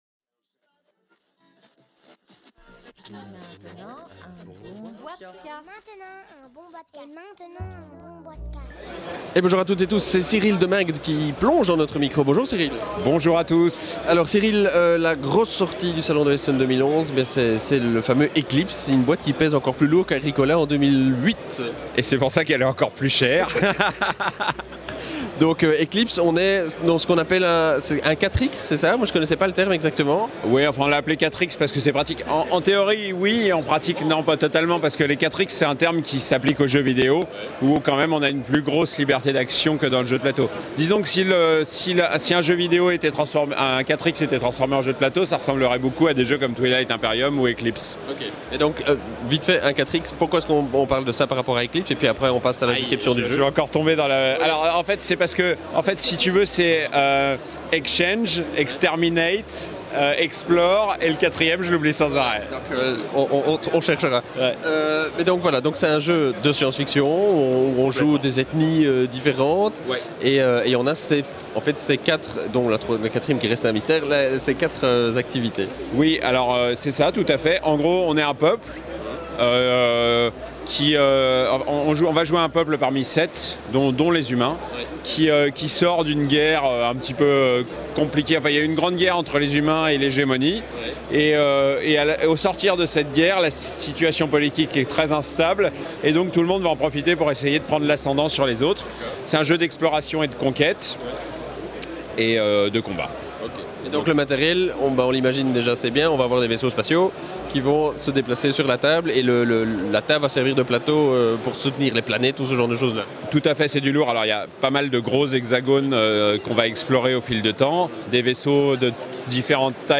enregistré au Salon international du Jeu de Société de Essen – Octobre 2011